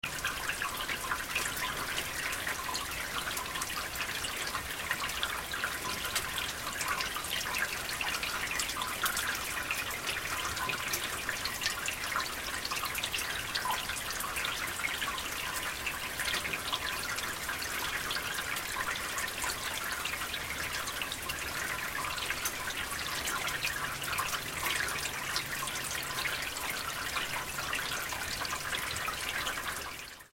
clark-river.mp3